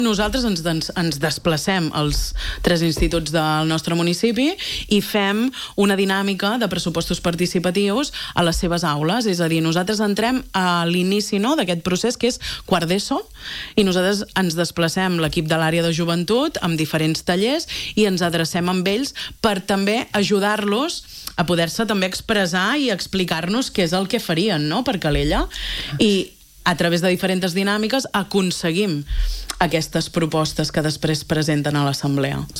La regidora de Joventut i Participació, Mariceli Santarén, n’ha parlat a l’entrevista del matinal de RCT.